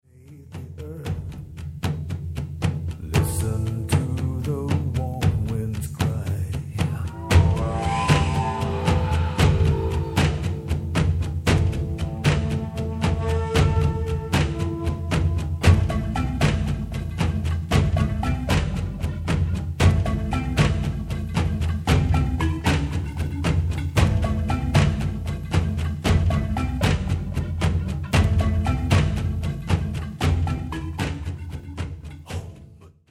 world music